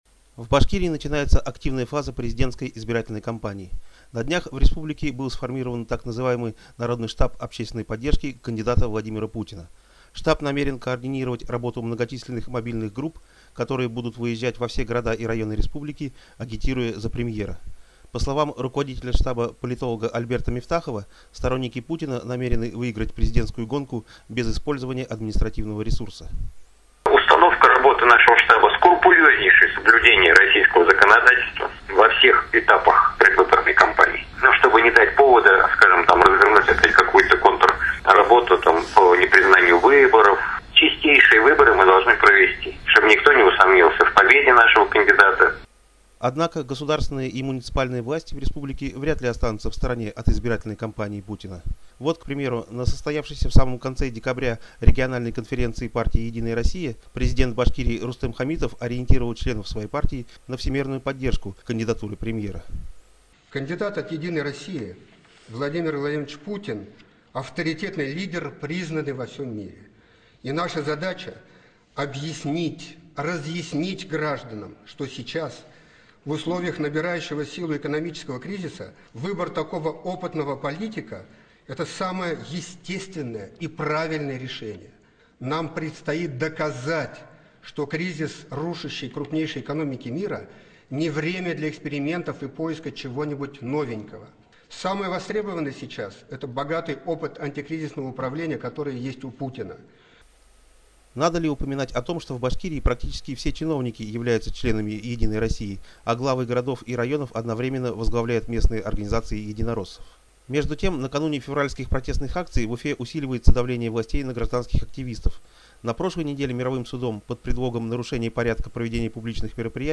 Агитация в Башкирии - репортаж